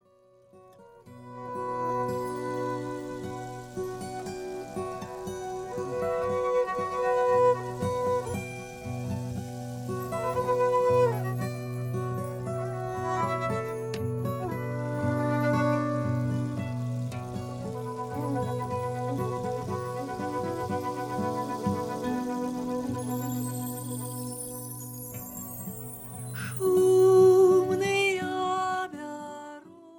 Belarussian folk song